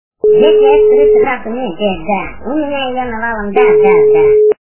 » Звуки » Смешные » Винни Пух - Если я скурю всю травку...
При прослушивании Винни Пух - Если я скурю всю травку... качество понижено и присутствуют гудки.